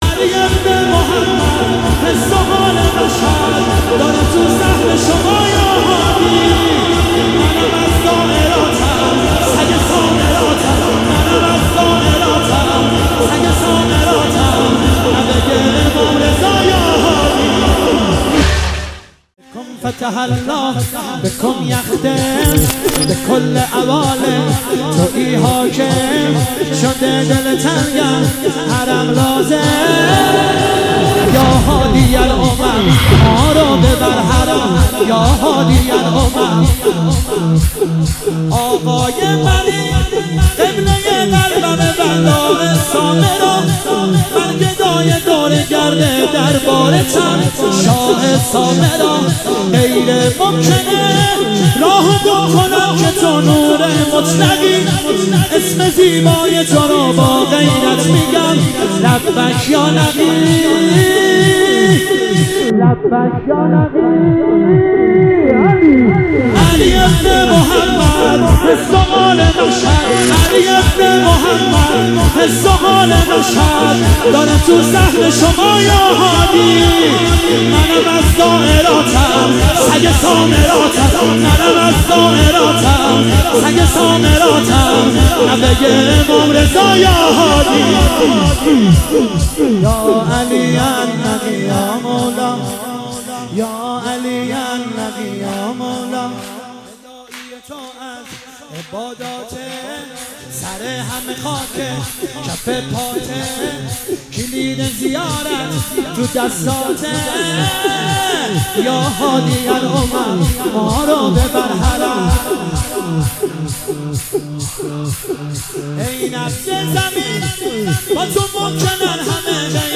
مداحی زمینه